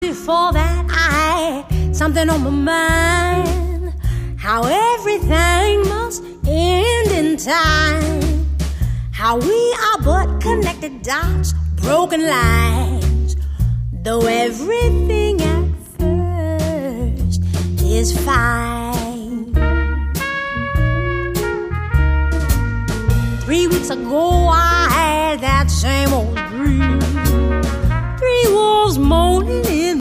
voce
Una voce di velluto tagliente.
Chiare tinte jazz